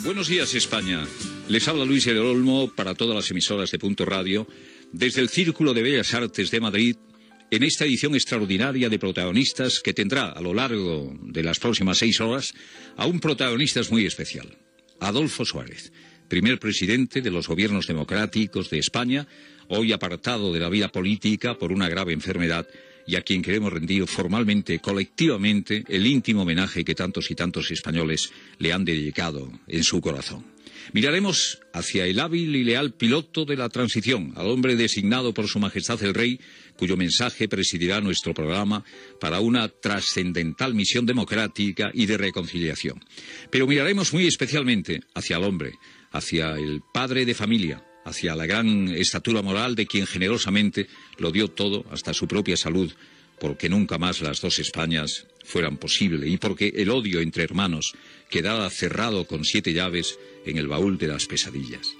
Especial des del Círculo de Bellas Artes de Madrid en homenatge a l'ex president del govern espanyol Adolfo Suárez.
Info-entreteniment